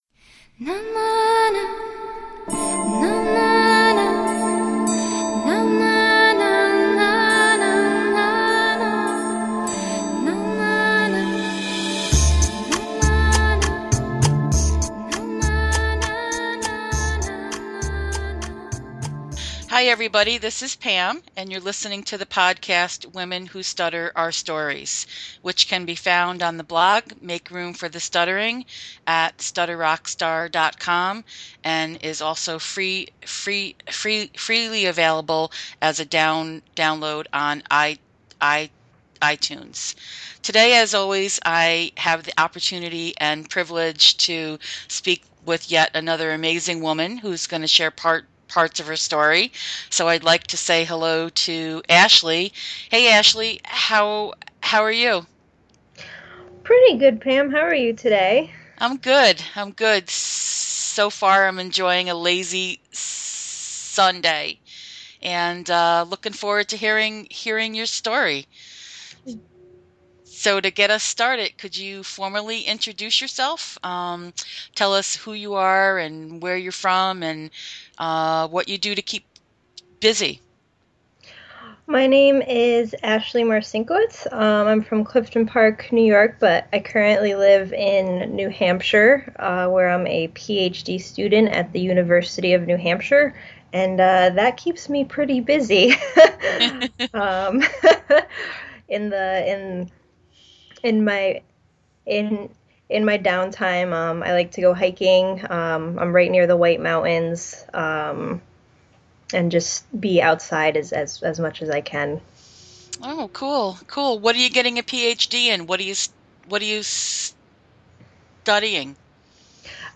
This was a great conversation, full of honesty and humor.